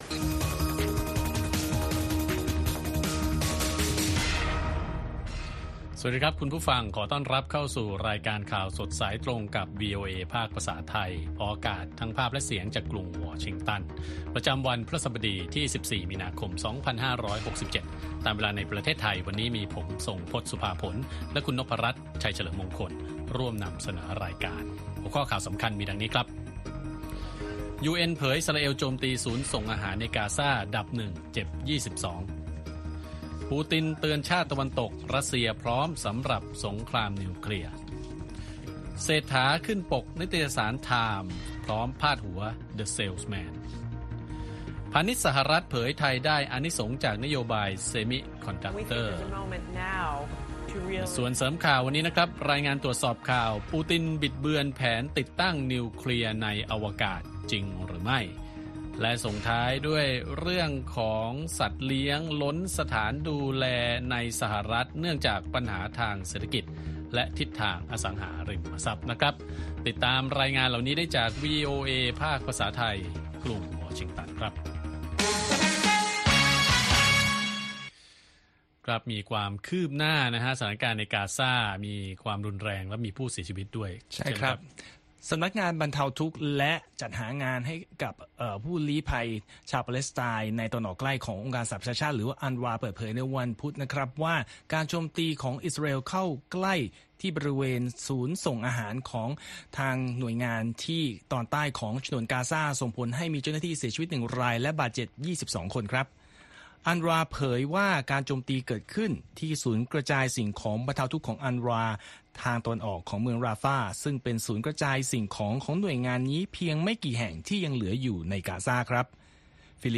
ข่าวสดสายตรงจาก วีโอเอไทย วันพฤหัสบดี ที่ 14 มี.ค. 2567